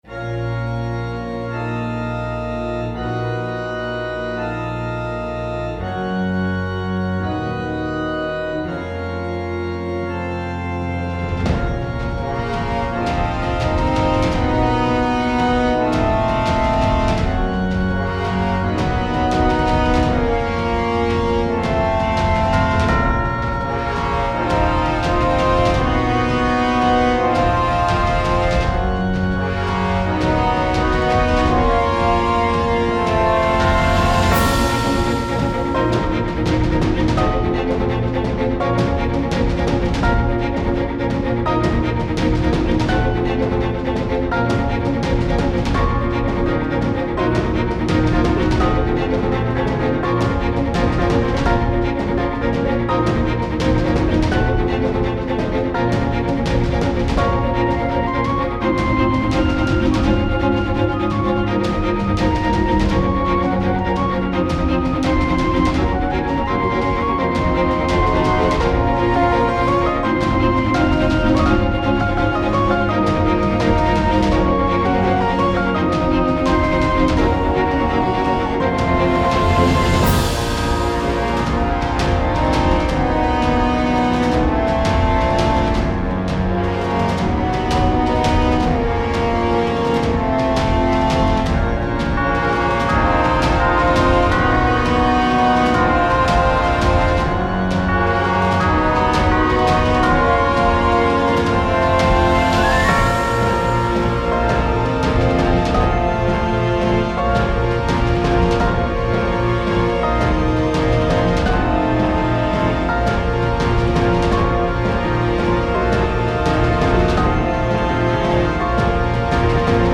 ファンタジー系フリーBGM｜ゲーム・動画・TRPGなどに！
互いの信念がぶつかり合う系戦闘曲。